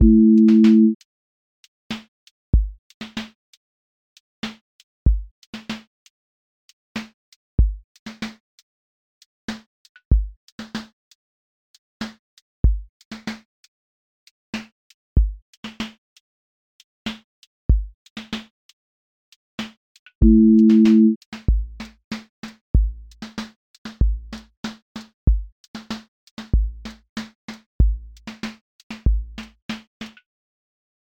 neo-soul live lilt pocket
• voice_kick_808
• voice_snare_boom_bap
• voice_hat_rimshot
• voice_sub_pulse
• tone_warm_body
• motion_drift_slow